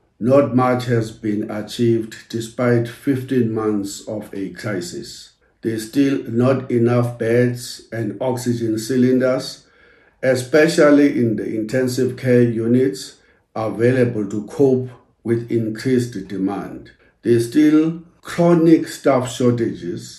Speaking to Kaya News Vavi say that all employers who disobey the restrictions or who unduly benefit from the crisis or who exploit workers under the guise of the pandemic.